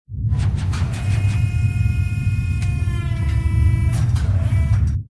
repair3.ogg